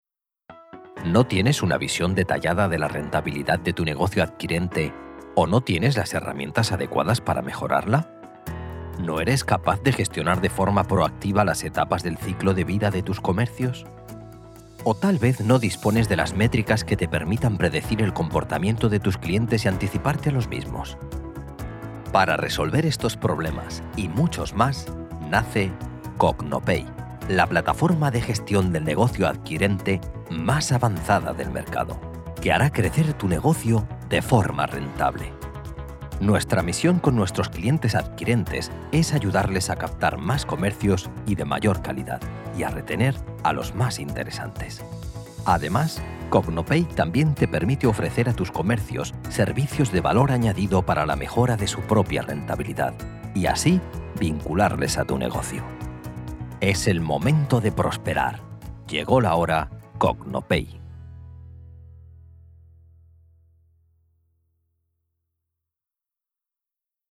Voz cálida y adaptable que transmite emociones.
Sprechprobe: Werbung (Muttersprache):